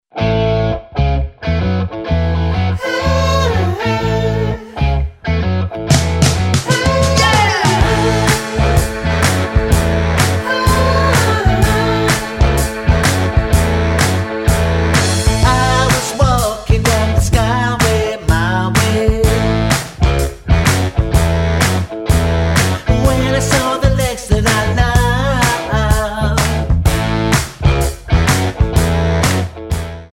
--> MP3 Demo abspielen...
Tonart:A Multifile (kein Sofortdownload.